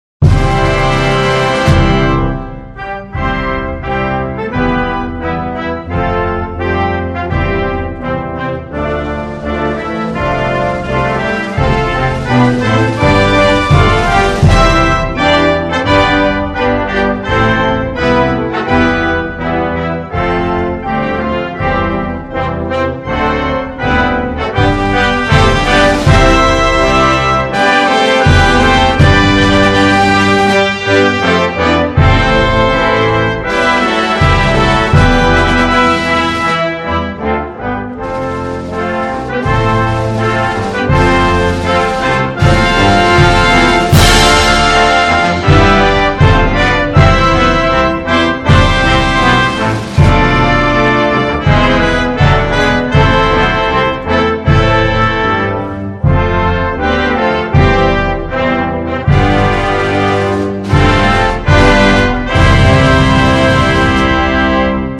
Russia-Anthem.mp3